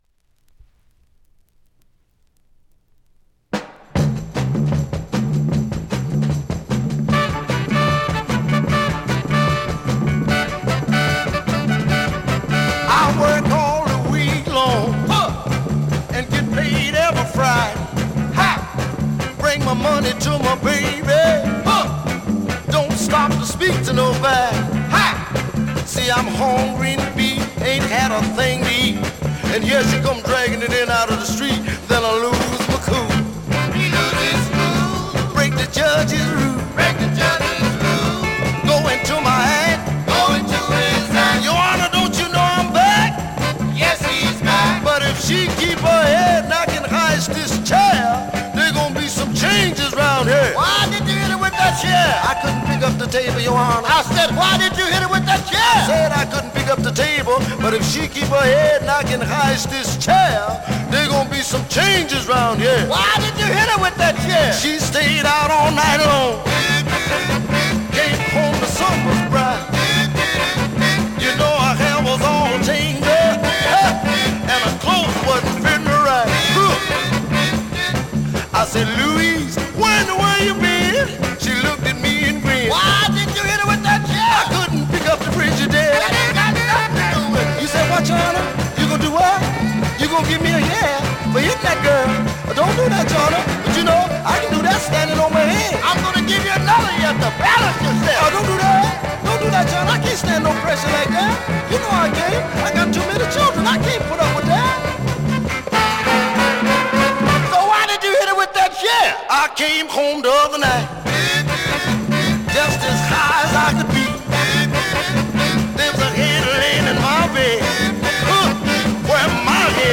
現物の試聴（両面すべて録音時間５分５６秒）できます。